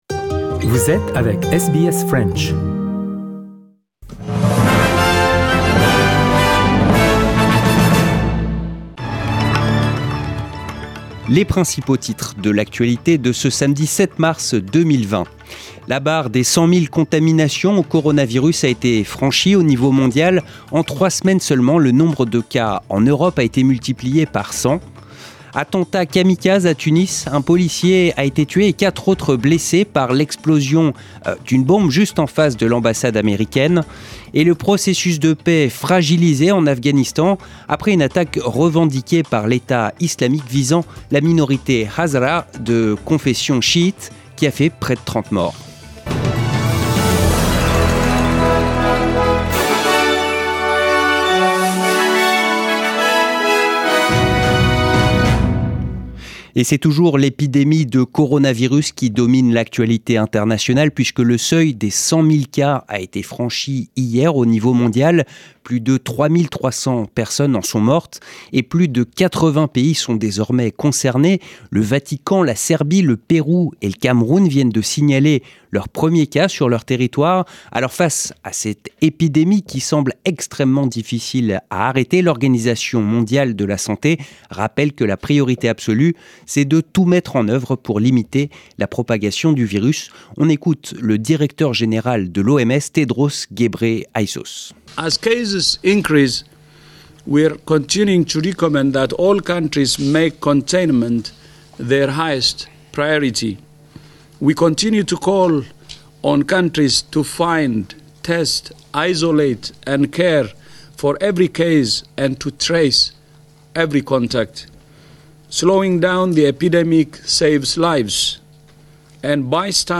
Le journal du 7 mars 2020